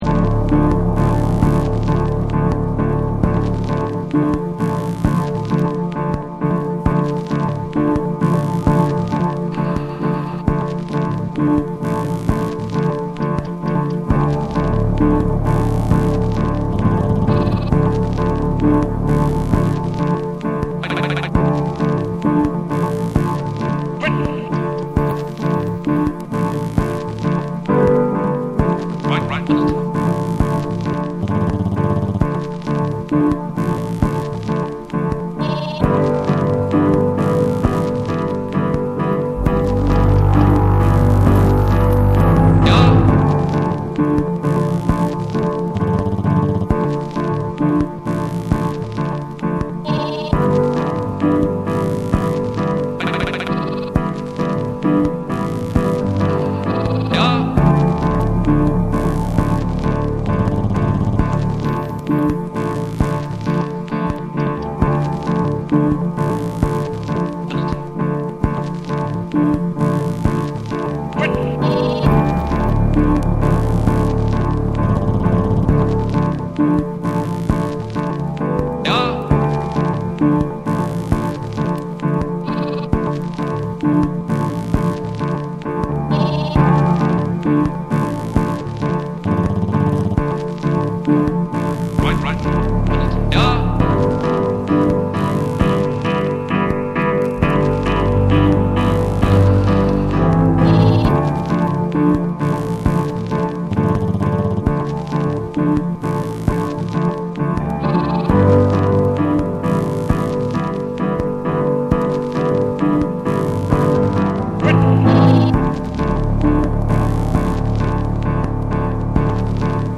BREAKBEATS / CHILL OUT